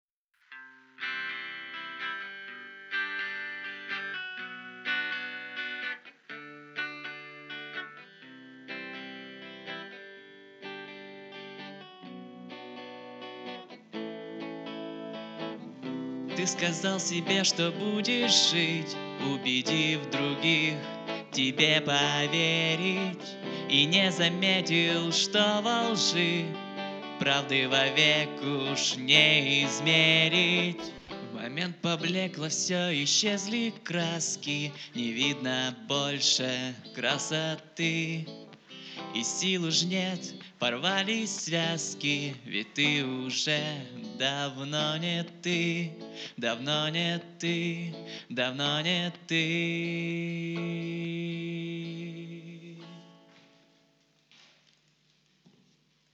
Рубрика: Поезія, Авторська пісня
это песня. спасибо smile